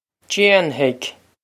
Pronunciation for how to say
Jain-hig
This is an approximate phonetic pronunciation of the phrase.